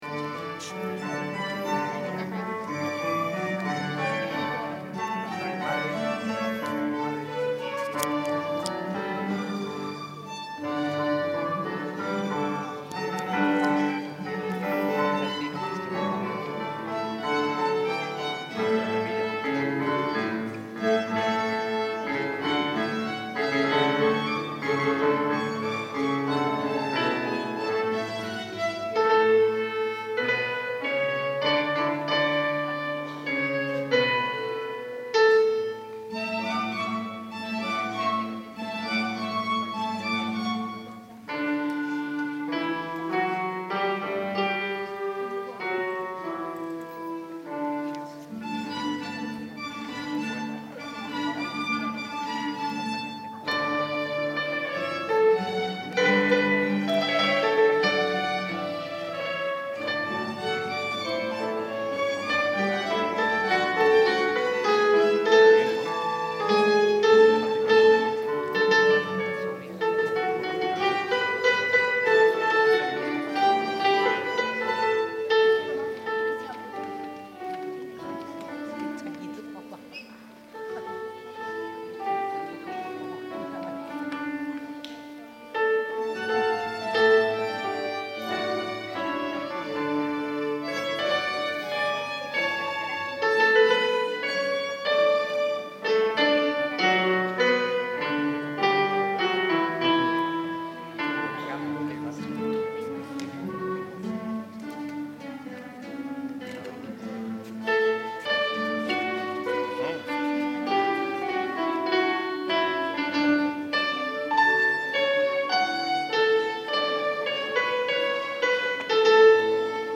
Flauta travesera
Clarinete en Si bemol
Guitarra 1
Guitarra 2
Piano
- Compás: 4/4.
- Tonalidad: Re Mayor
• Interpretaciones en directo:
Concierto completo
Concierto_Aleluya.MP3